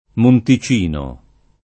Monte, -ti (fra gli altri, i Monti, nome di uno dei rioni di Roma) — come top., spec. nella forma sing., spesso completato da un altro elem., graficam. unito o diviso secondo i casi: Monte-Carlo e Montecarlo, Monte Isola o Montisola, Montechiarugolo, Monte Sante Marie — dim. monticello [monti©$llo], monticino [
monti©&no] — cfr.